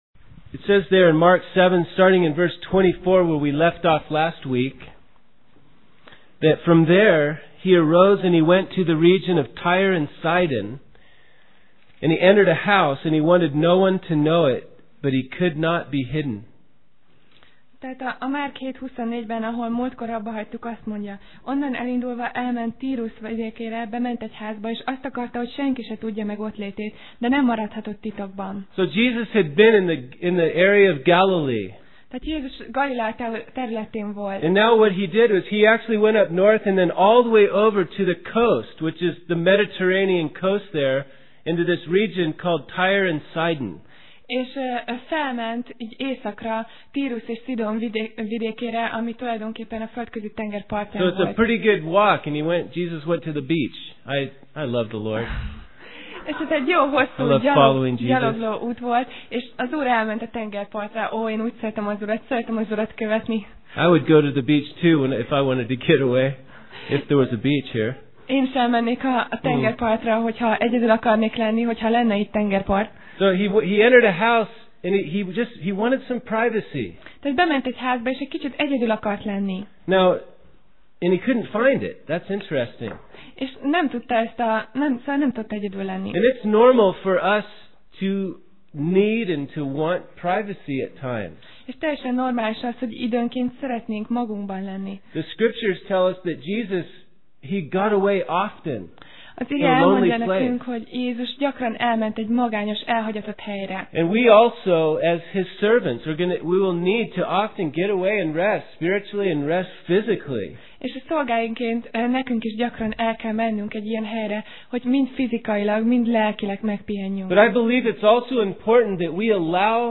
Passage: Márk (Mark) 7:24-30 Alkalom: Vasárnap Reggel